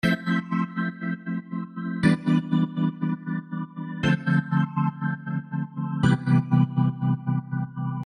标签： 120 bpm RnB Loops Pad Loops 1.35 MB wav Key : Unknown
声道立体声